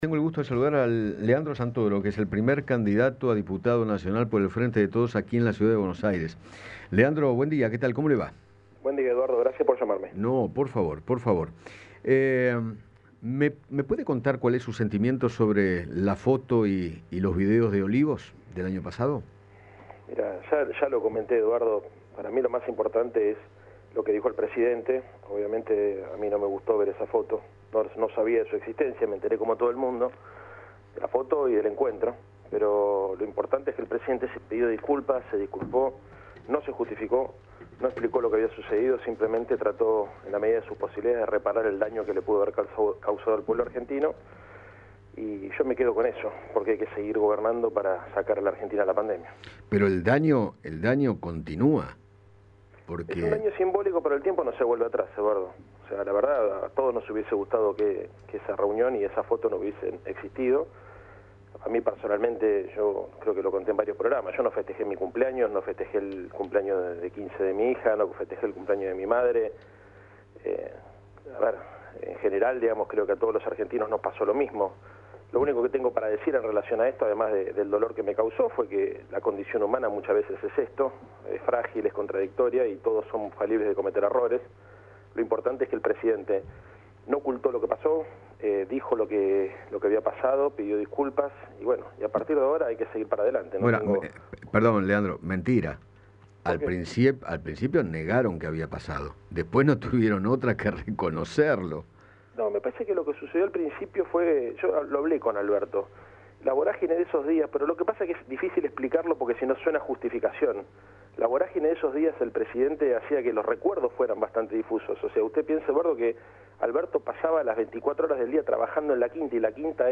Leandro Santoro, precandidato a diputado nacional por el Frente de Todos, conversó con Eduardo Feinmann acerca del escándalo de Olivos, las disculpas del presidente, y comentó cuáles son las propuestas del Frente de Todos en CABA.